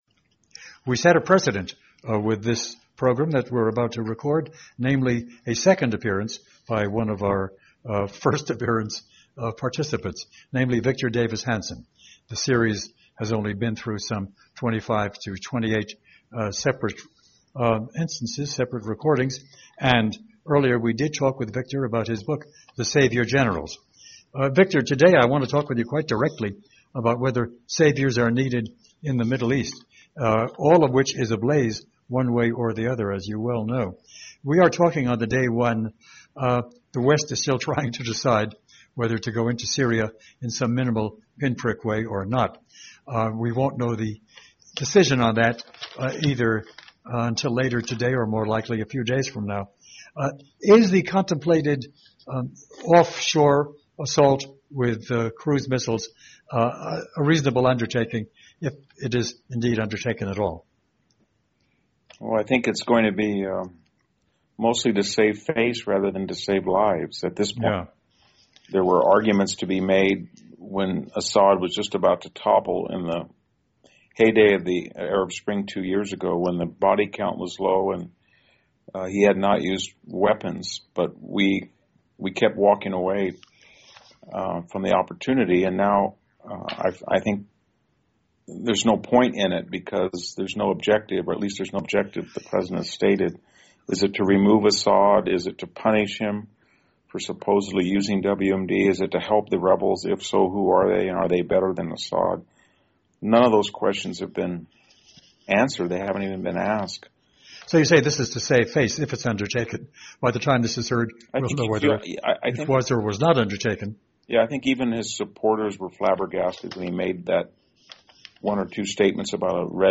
Milt Rosenberg is a daily podcast that features provocative and thought provoking discussion centered on the world of ideas.
In today’s episode, Victor Davis Hanson joins us once again. The Middle East is seething with trouble, discord, danger and the inhumane murder of civilians in Syria, Lebanon, Iraq, Egypt.